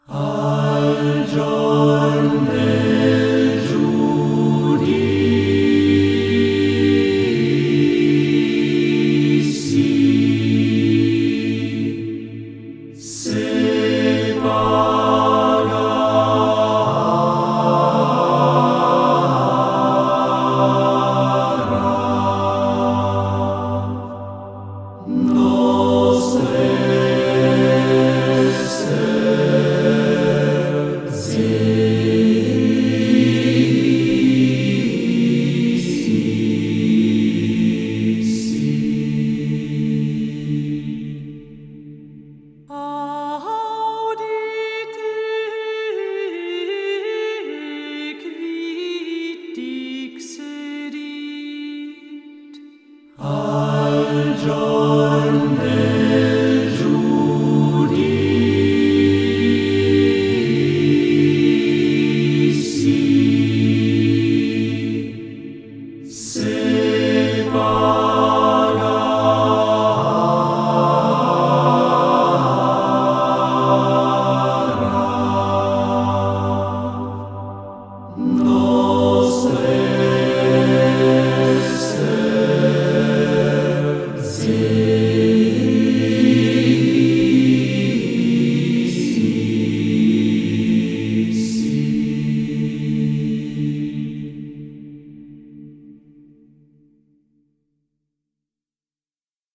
medieval music